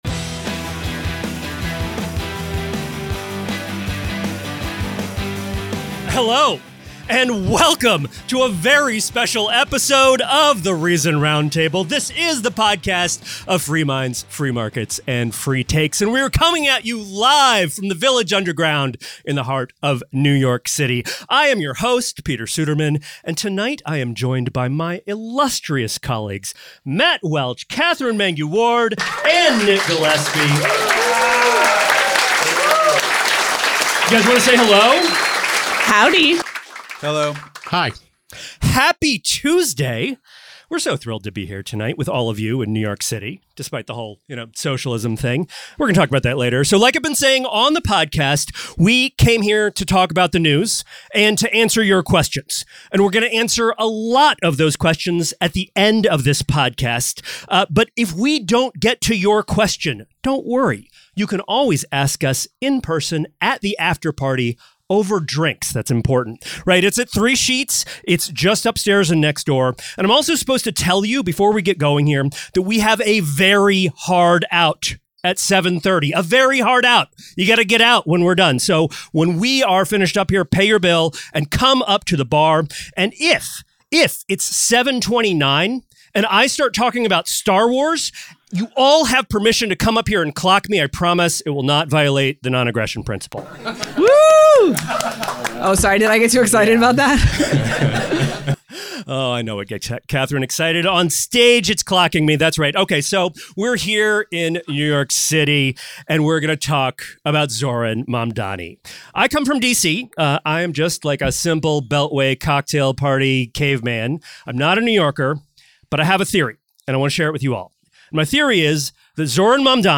for a live episode of The Reason Roundtable from New York City. It will be a night of unscripted, uncensored, and unhinged conversation about whatever the hell is happening in America this week—including the bizarre reality that President Donald Trump and New York mayoral candidate Zohran Mamdani each represent a kind of populism. What does it mean when a word applies to both a billionaire president and a leftist intellectual?